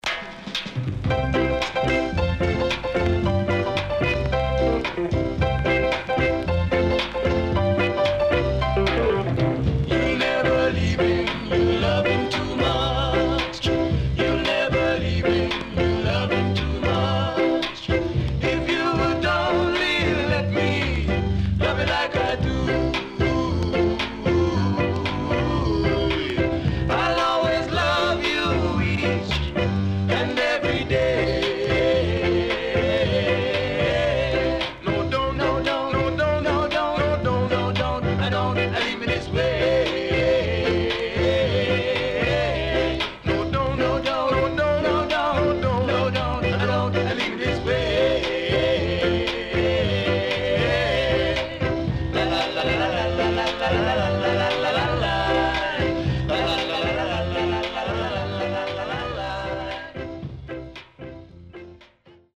66年 W-Side Great Ska Vocal
SIDE B:うすいこまかい傷ありますがノイズ目立ちません。